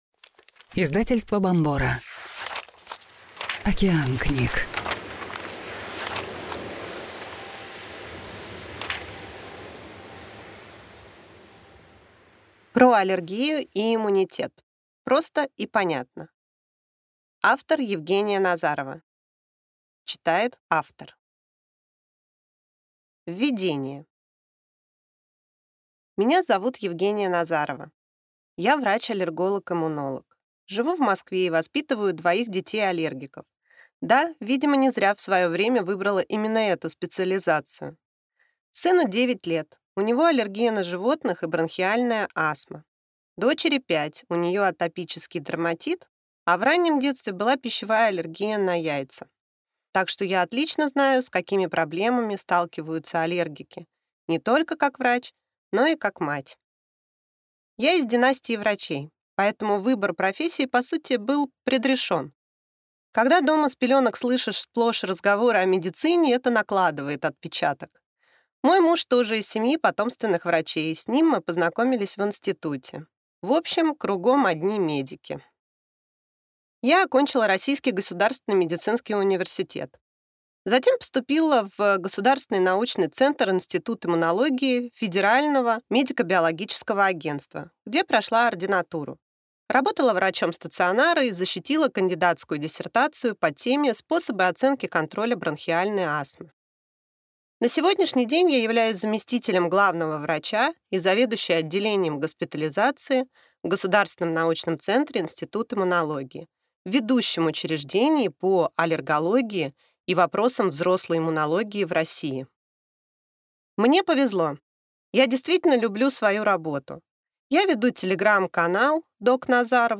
Аудиокнига Про аллергию и иммунитет. Просто и понятно | Библиотека аудиокниг